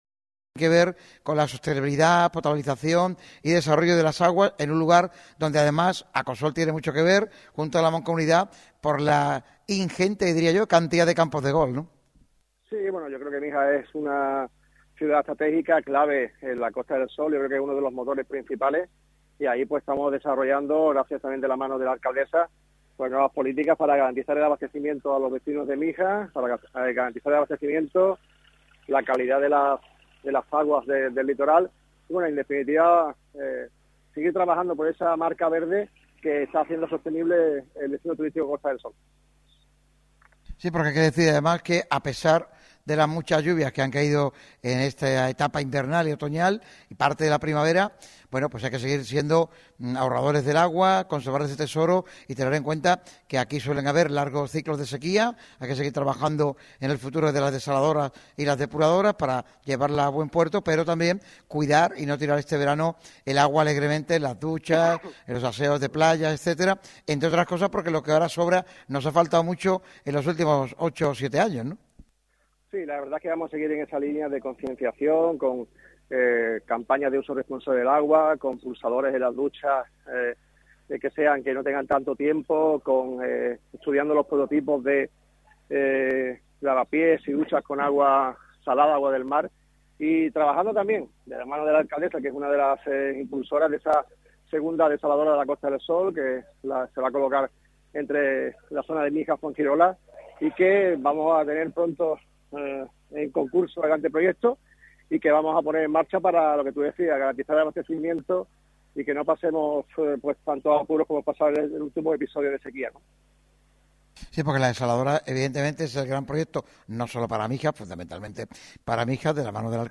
Manolo Cardeña, presidente de la Mancomunidad de Municipios de la Costa del Sol y presidente de ACOSOL, se ha pasado por el programa especial de Radio MARCA Málaga dedicado al deporte mijeño que se ha celebrado en el salón de actos de la Tenencia de Alcaldía de la Cala de Mijas.